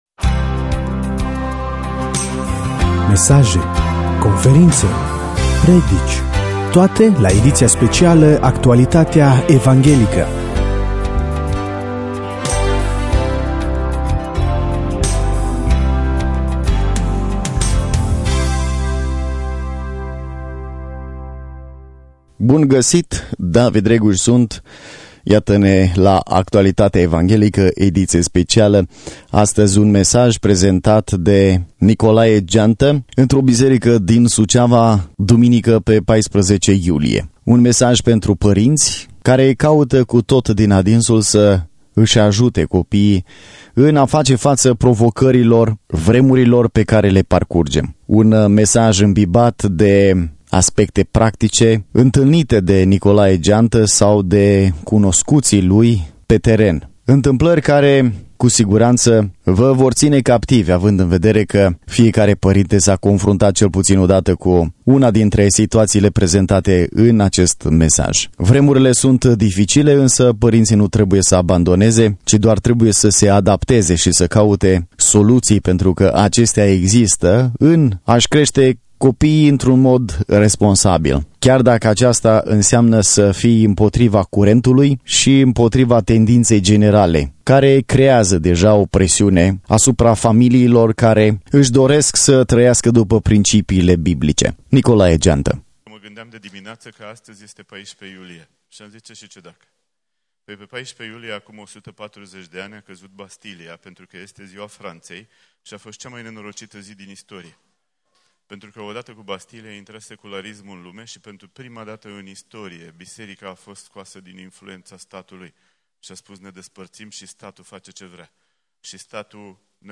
Mesaj biblic